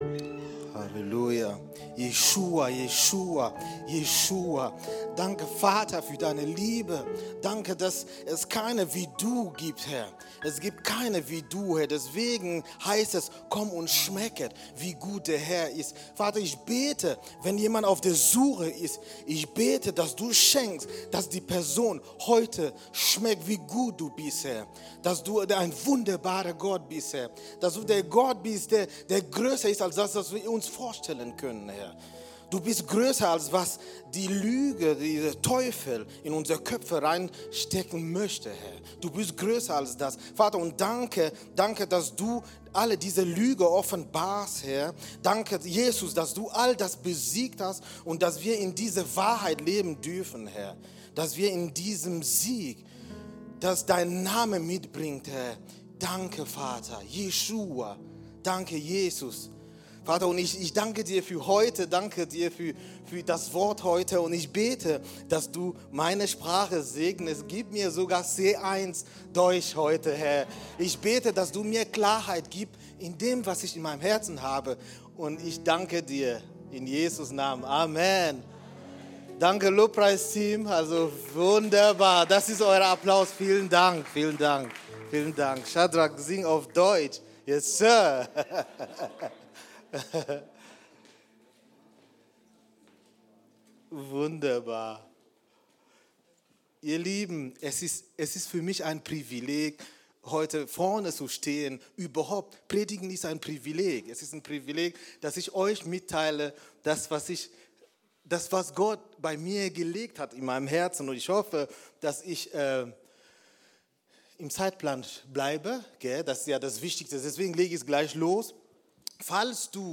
Predigt von 2. Mose 33:7-11 am 03.08.2025